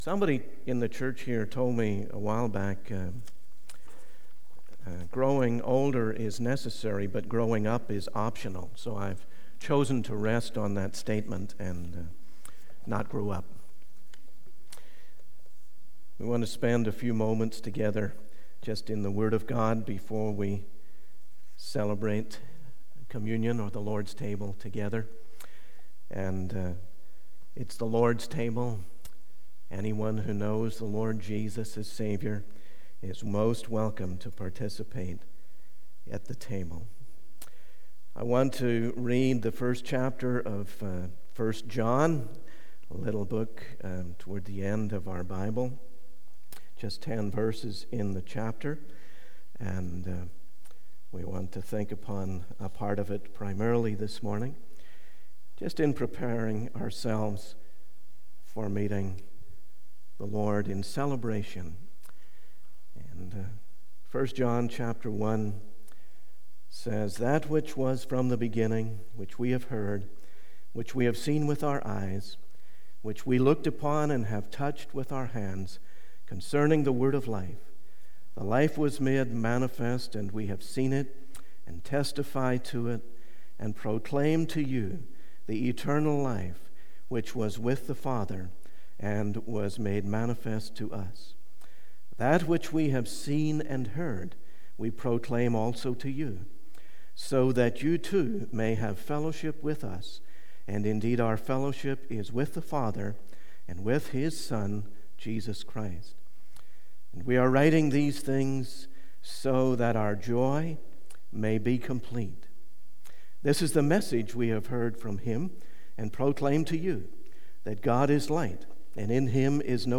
In this sermon, the preacher reflects on a past experience of watching a video of himself preaching in 1990.